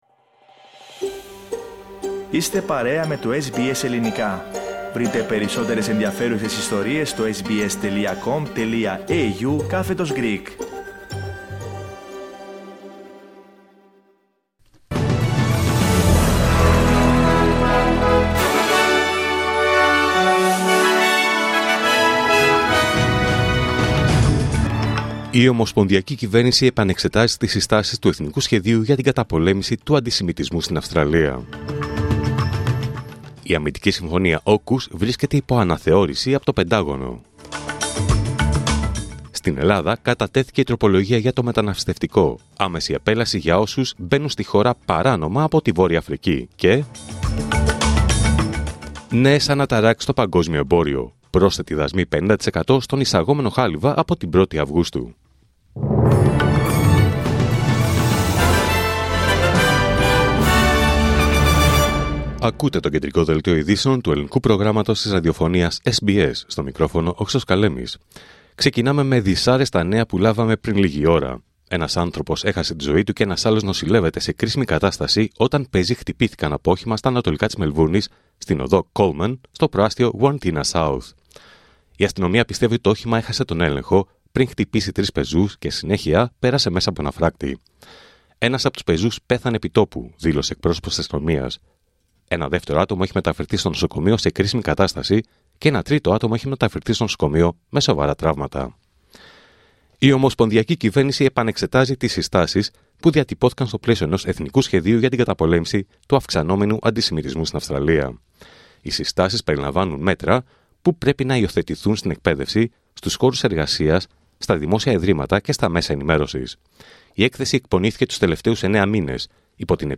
Δελτίο Ειδήσεων Πέμπτη 10 Ιουλίου 2025